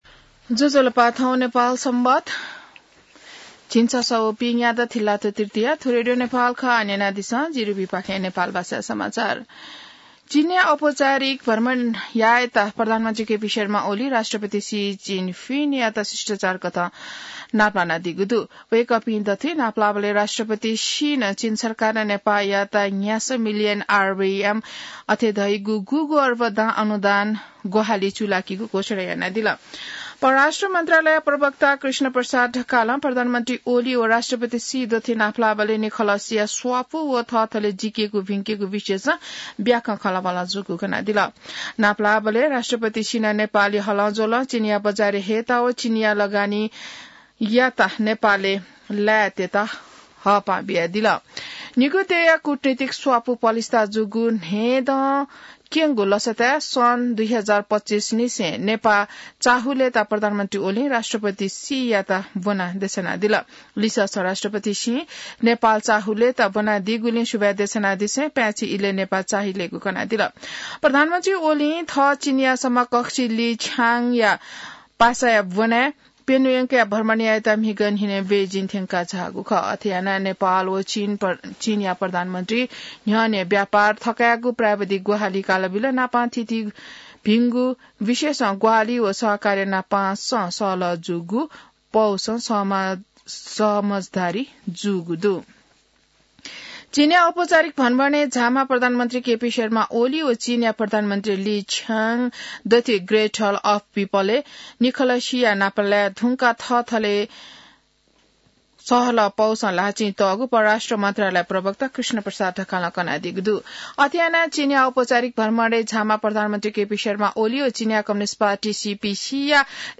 नेपाल भाषामा समाचार : २० मंसिर , २०८१